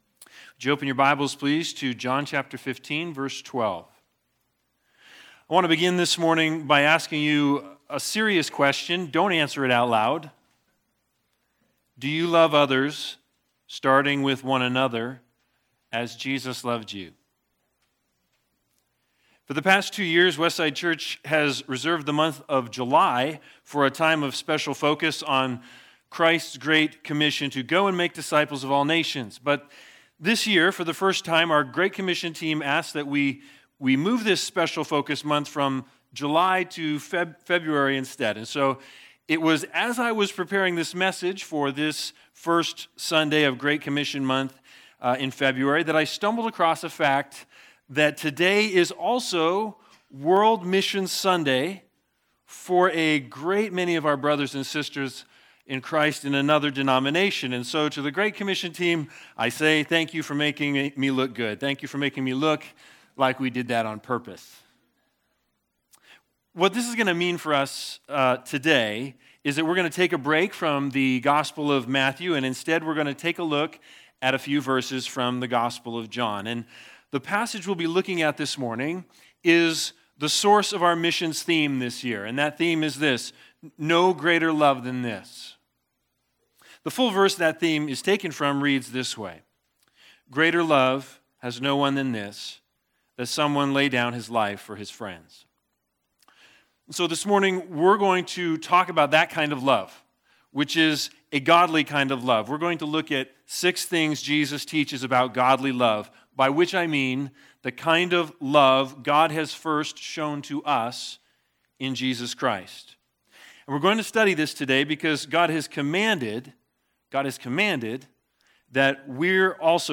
John 15:12-16 Service Type: Missions THE BIG IDEA